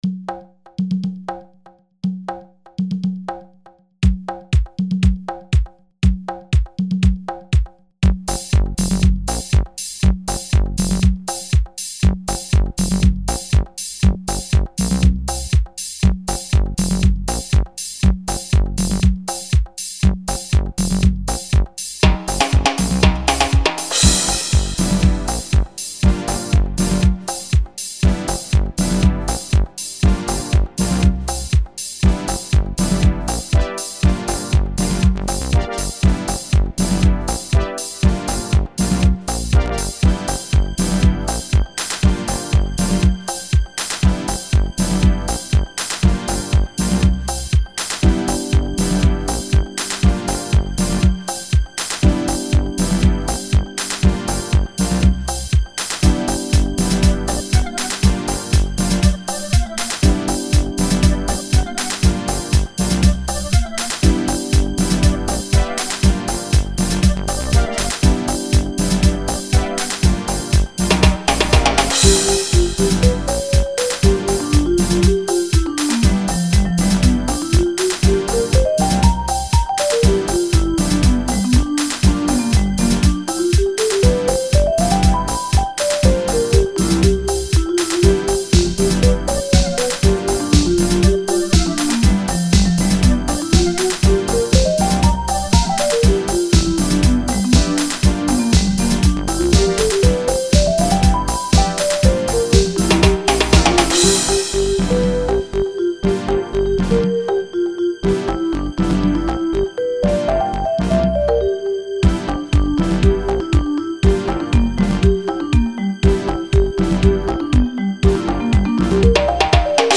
Disco lite mer i stil som Those Norwgians. Alla trummor är Linn drum samplingar utom virveln som ni får dubbel guldstjärna om ni kan lista ut var jag samplat den (orakade inte syntesera fram en egen som skulle låta likadant).
Allt är gjort i Reason 1.0.
disco.mp3